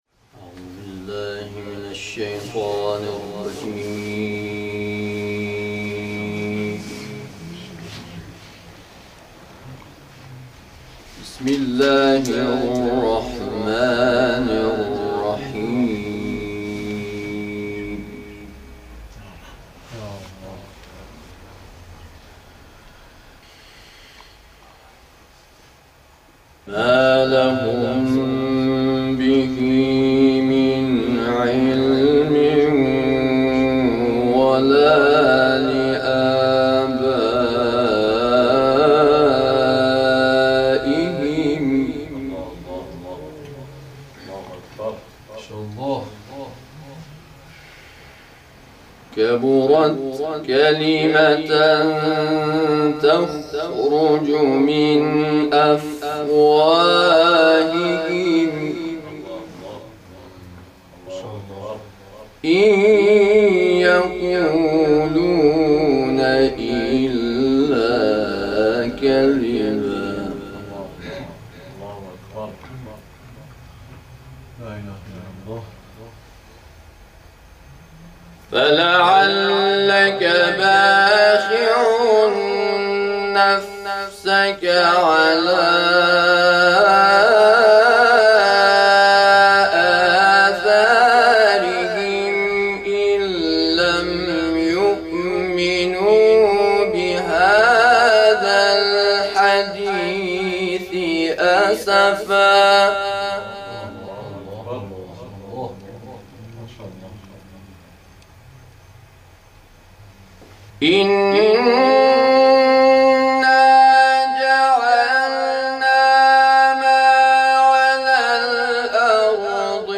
تلاوت سوره «کهف»